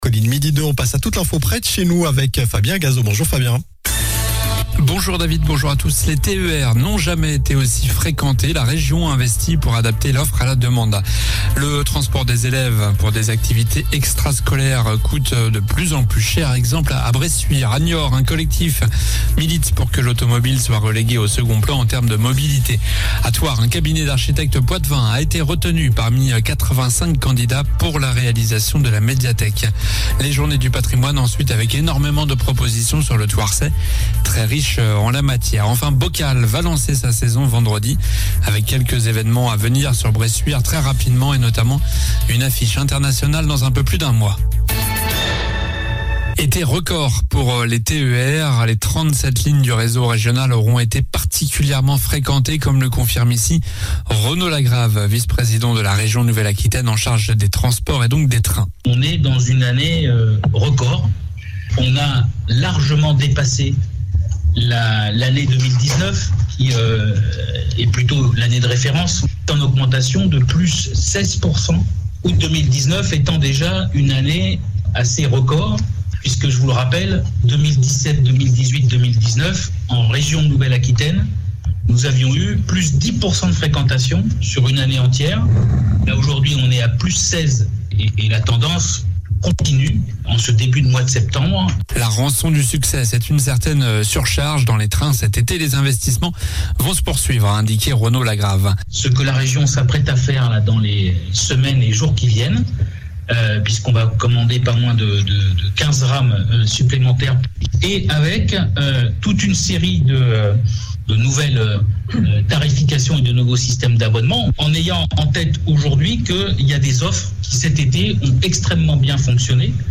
COLLINES LA RADIO : Réécoutez les flash infos et les différentes chroniques de votre radio⬦
Journal du vendredi 14 septembre (midi)